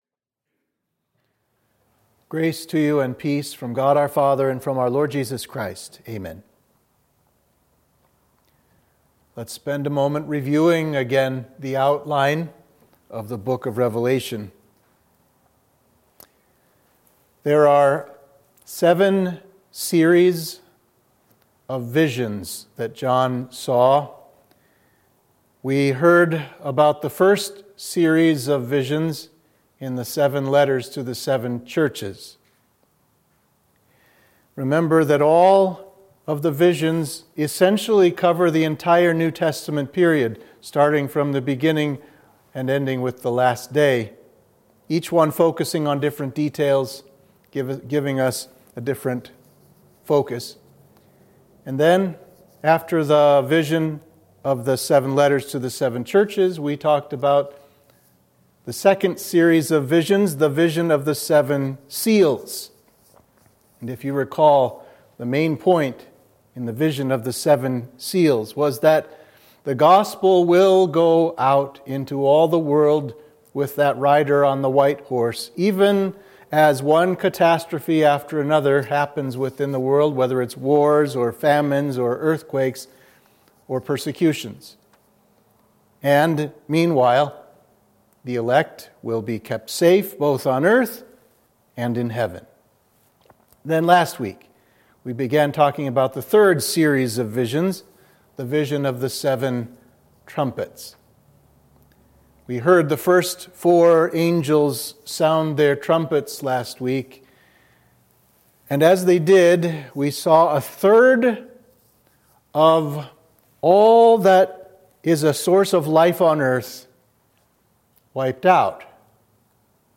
Sermon for Midweek of Easter 2